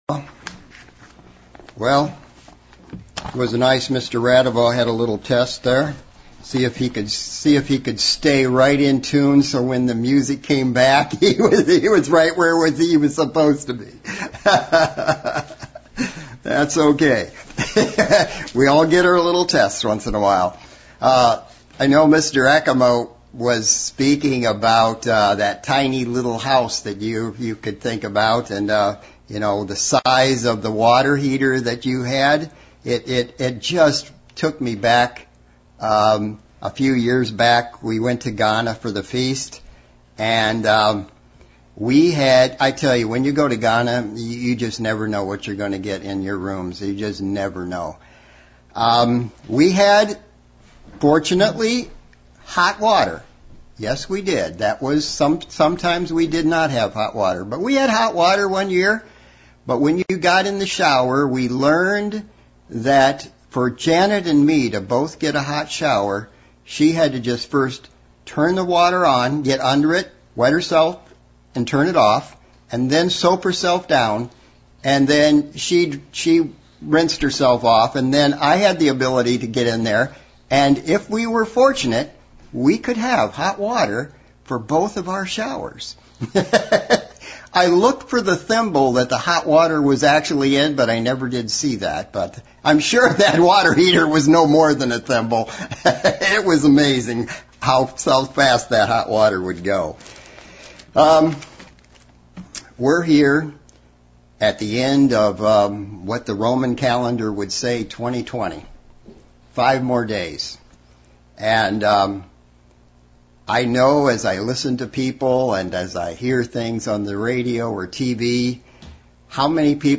Split sermon lookng at the example of King Jeroboam of Israel and why he was so bad. Jeroboam set the bar so high that all the other evil kings of Israel were compared to him.